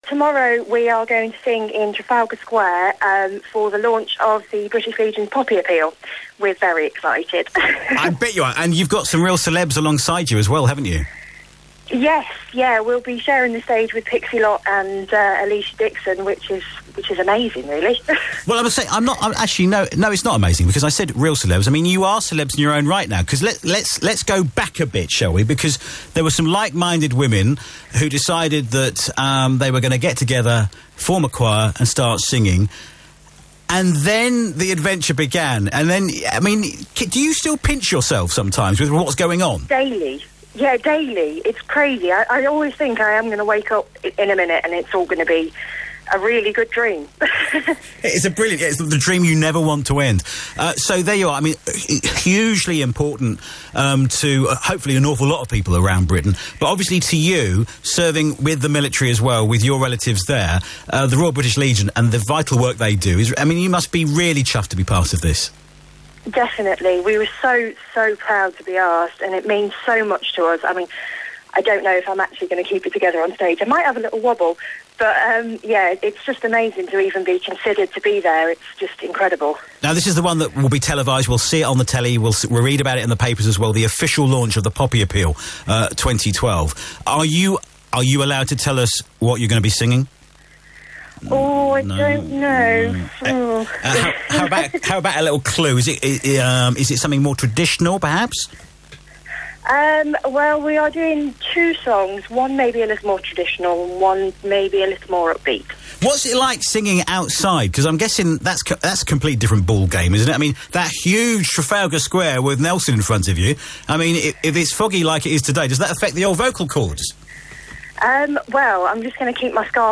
Town 102 Breakfast